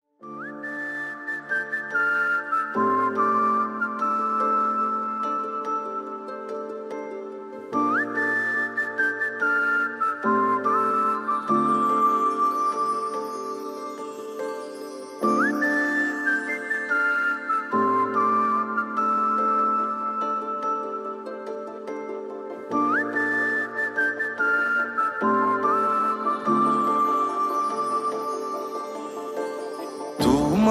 Gujarati Ringtones